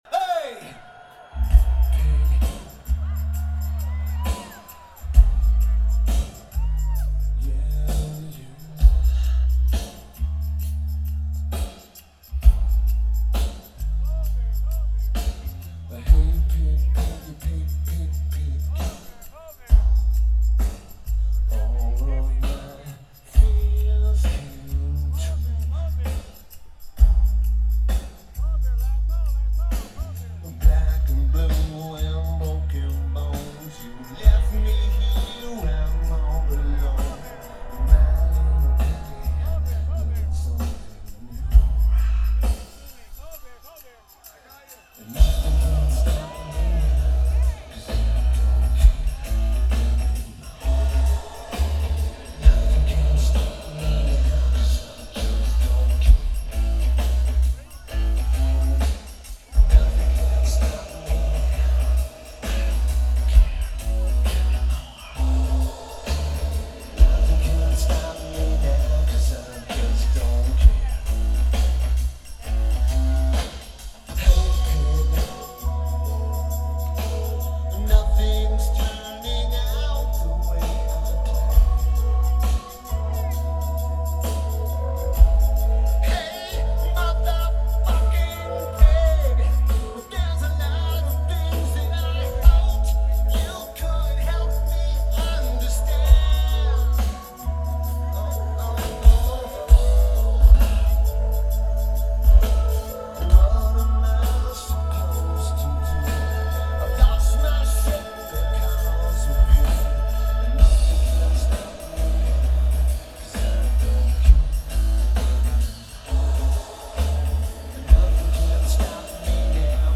Welcome To Rockville
Lineage: Audio - AUD (Zoom H4n Pro)